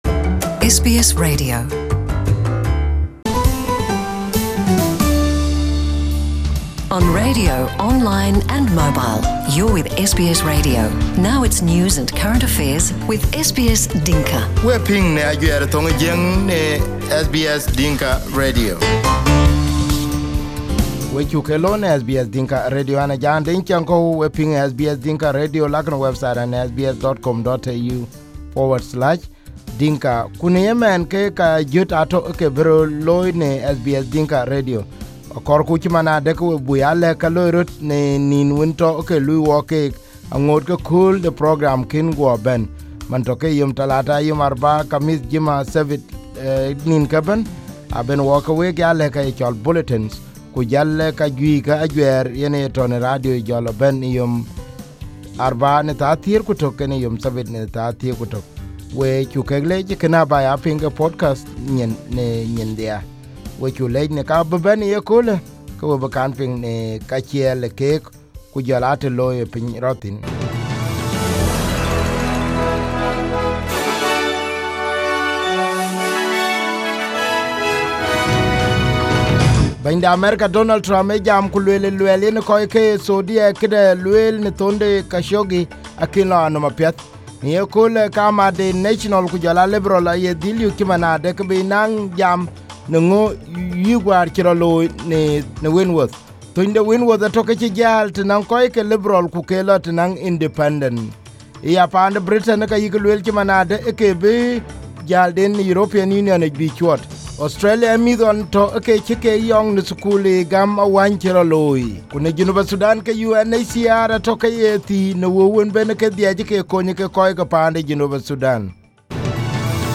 SBS Dinka Radio News 23/10/2018: Counting continues in the Sydney seat of Wentworth